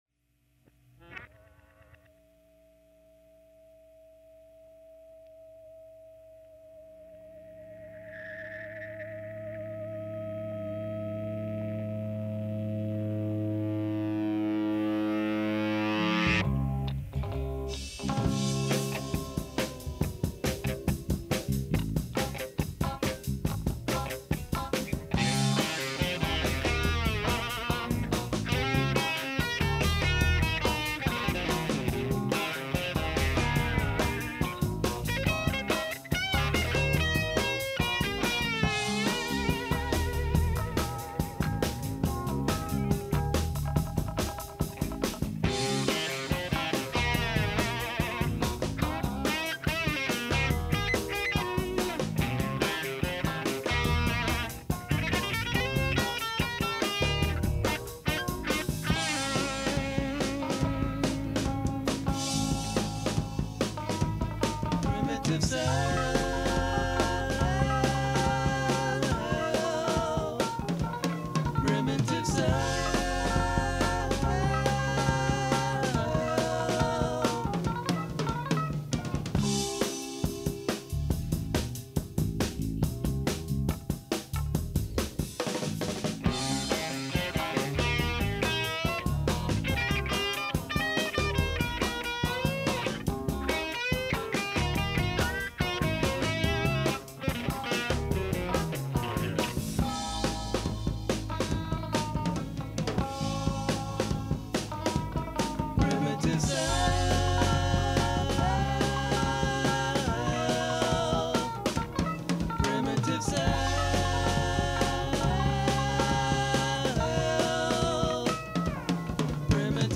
percussion
bass guitar
keyboards
guitar, vocals